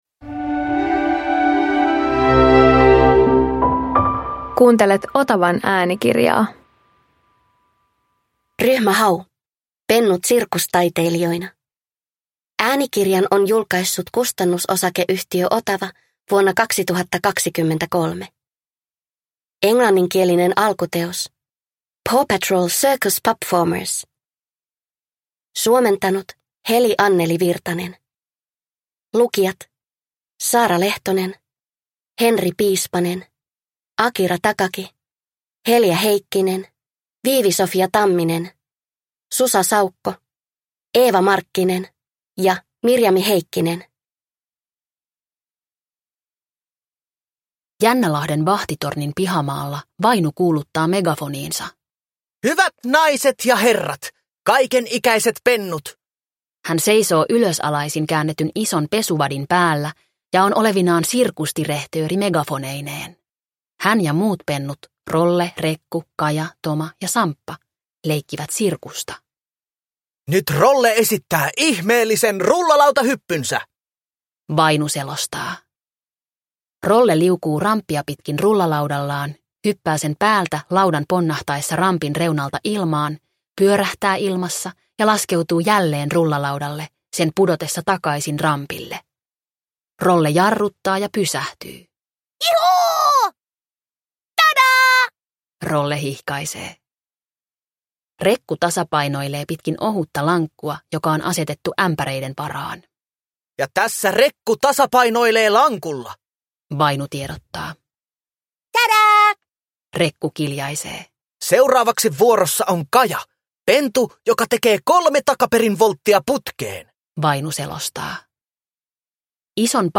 Ryhmä Hau - Pennut sirkustaiteilijoina – Ljudbok